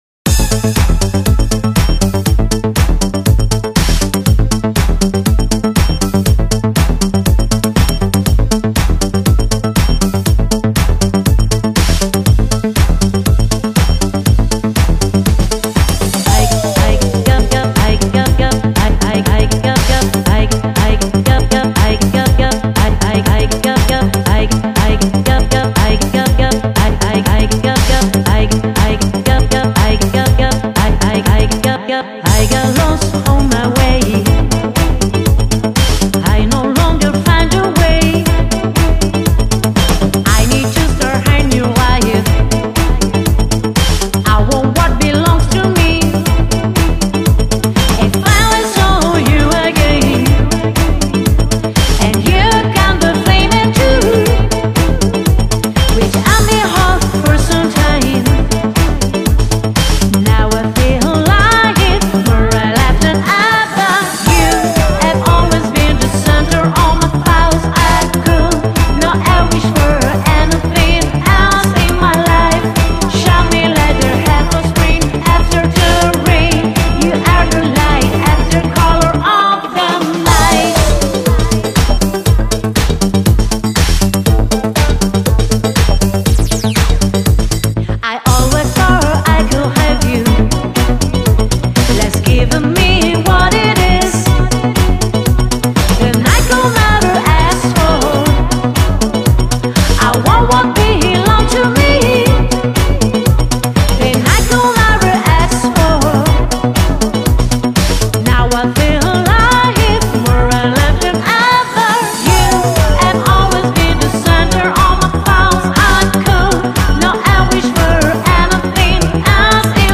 Remix Feel